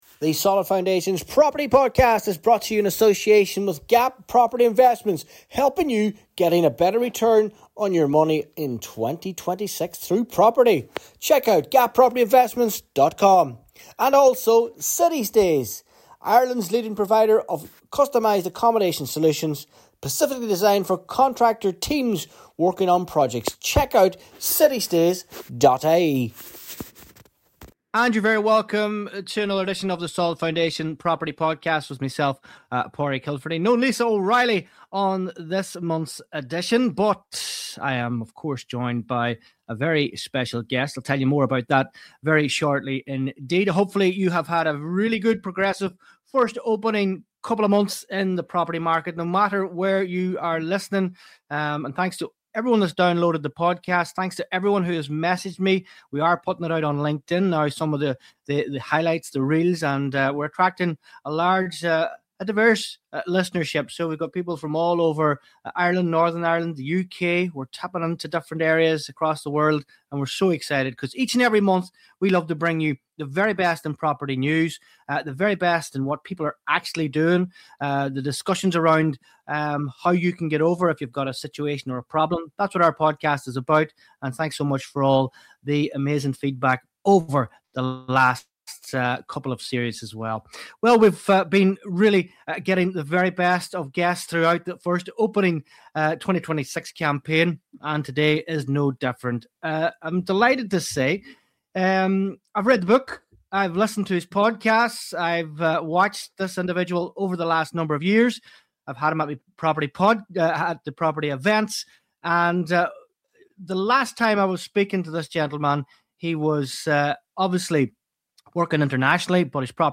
This is a really insightful conversation packed with practical advice and real-world experience.